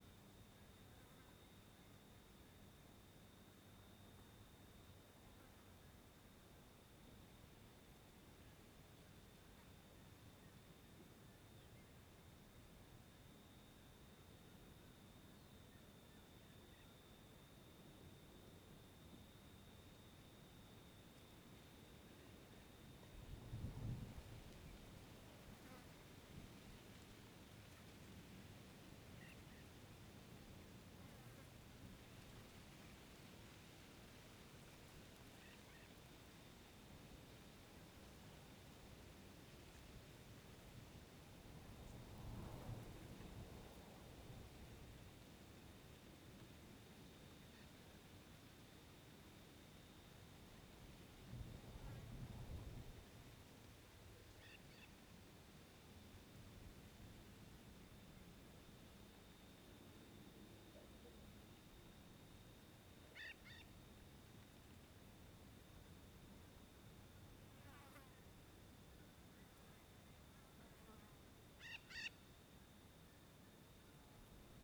Ambiente natural em fim de tarde com cigarra, mosca e vento Ambiente externo , Ambiente natural , Árvores , Cigarra , Dia , Mosca , Pássaros , Tarde , Vento Goiás Velho Stereo
CSC-05-001-LE - Ambiente natural em fim de tarde com cigarra, mosca e vento.wav